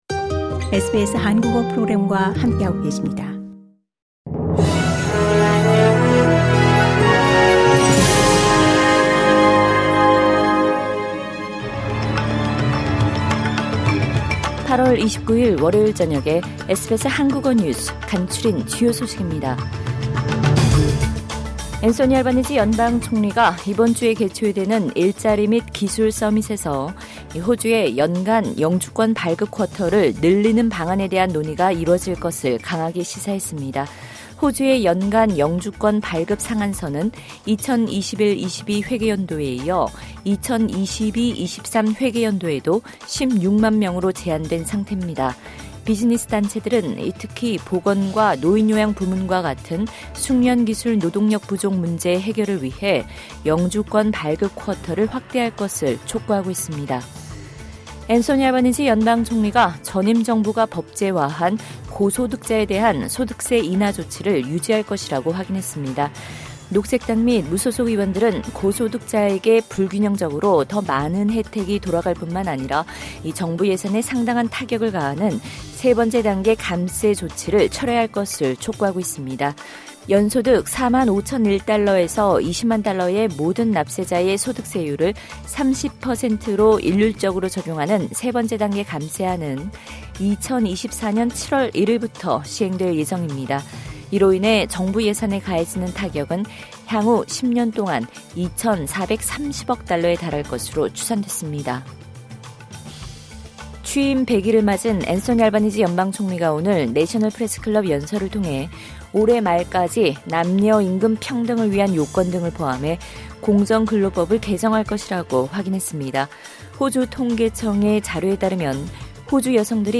SBS 한국어 저녁 뉴스: 2022년 8월 29일 월요일
2022년 8월 29일 월요일 저녁 SBS 한국어 간추린 주요 뉴스입니다.